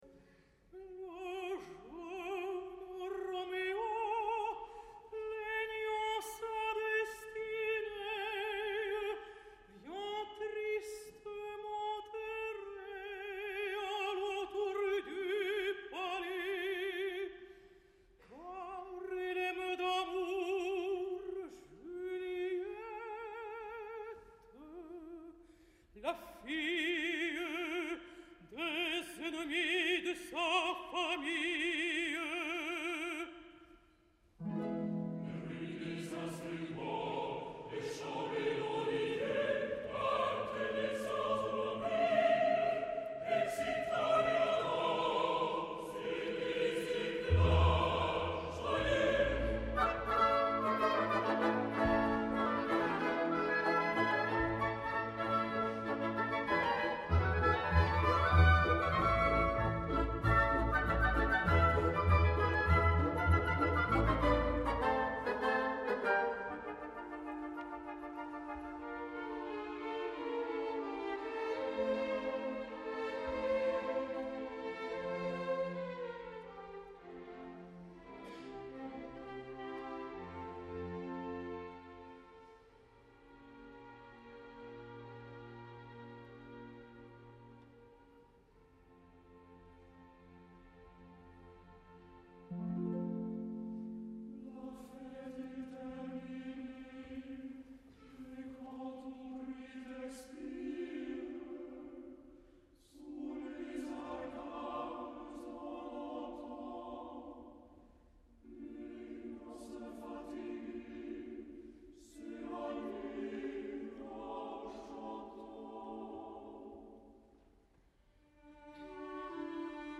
Avui torna per la porta gran, acompanyat de Olga Borodina i Ildar Abdrazakov, per interpretar sota les ordres del gran Riccardo Muti, la simfonia dramàtica Roméo et Juliette d’Hector Berlioz. La part de tenor és curta, però l’ocasió és important i els artostes que l’acompanyen, de primeríssim nivell.
El concert va tenir lloc abans d’ahir (7 de novembre de 2008) a la sala de Residenz de Munic, dins la temporada de l’orquestra de la capital bavaresa.